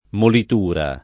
[ molit 2 ra ]